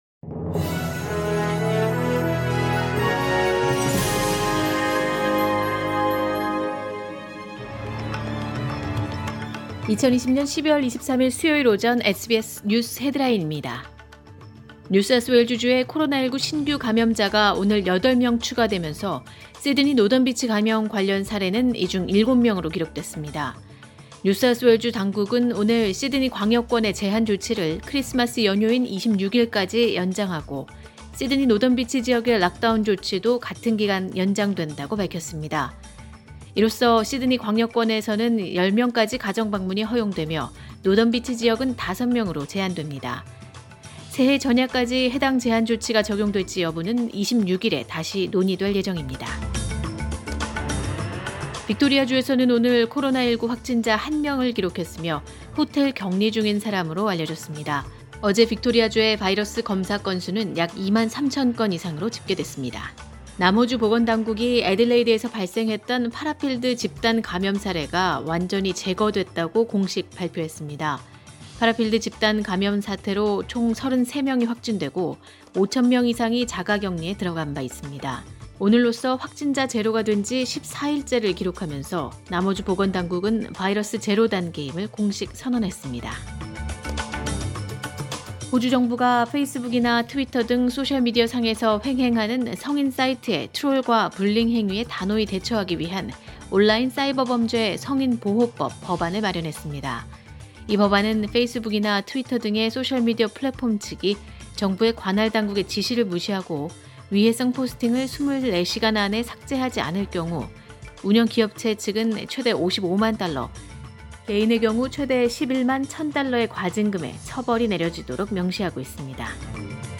2020년 12월 23일 수요일 오전의 SBS 뉴스 헤드라인입니다.